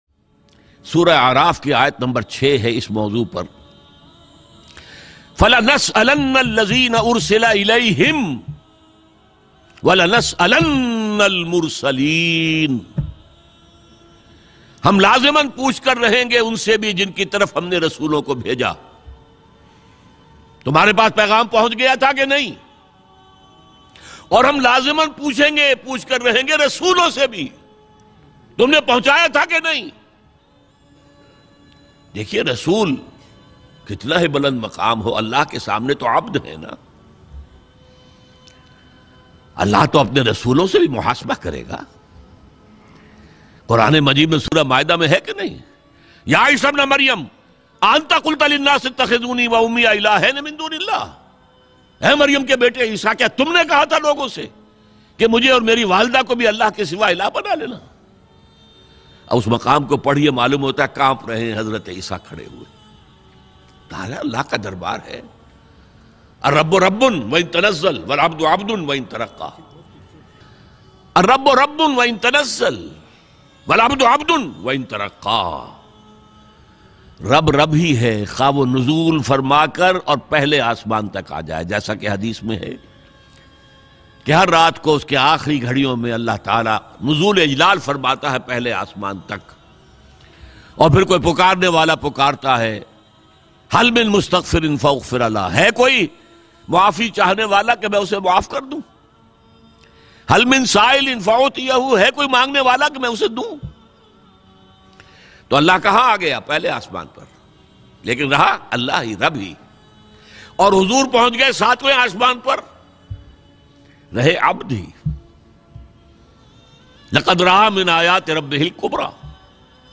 Aik Din Hisab Ho Ga Bayan MP3 Download Dr Israr Ahmed Very Emotional MP3.
Aik Din Hisab Ho Ga Bayan MP3 Downlaod Dr Israr Ahmed